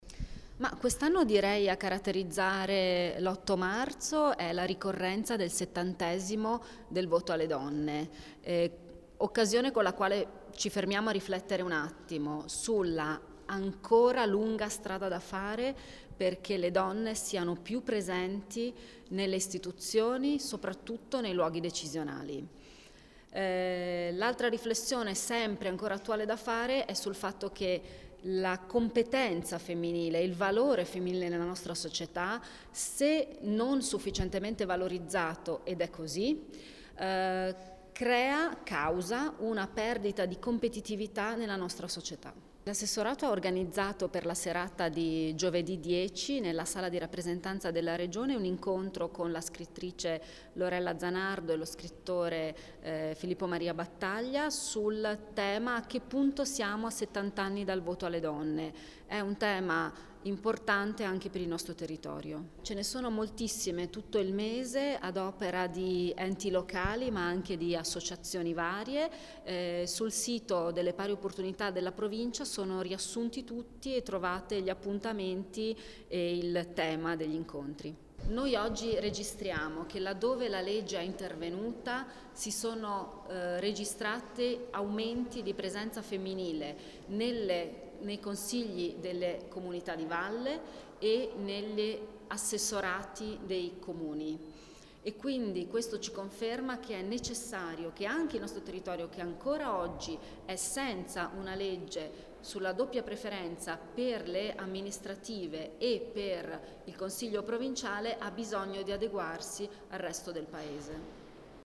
Ass_Ferrari_Interv_per_8_Marzo_MP3_256K.mp3